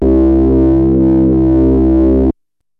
OSCAR OBOE 2.wav